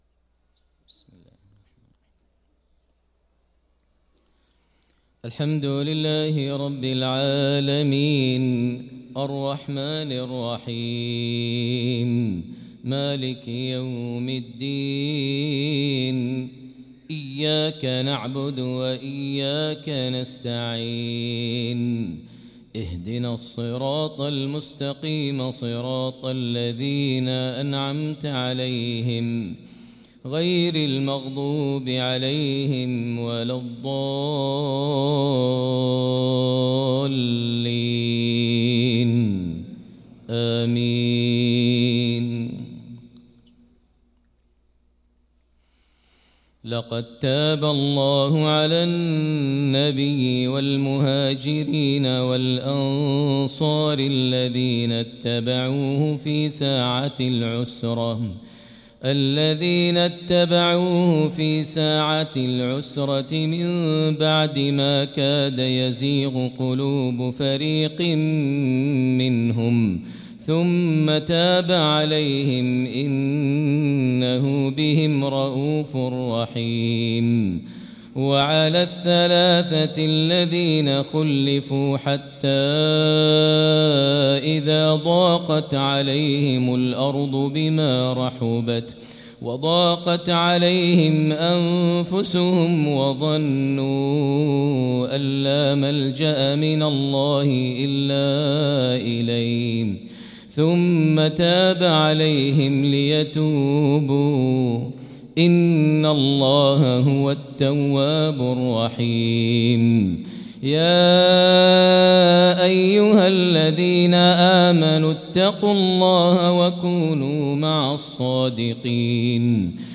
صلاة الفجر للقارئ ماهر المعيقلي 21 رجب 1445 هـ
تِلَاوَات الْحَرَمَيْن .